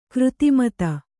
♪ křti mata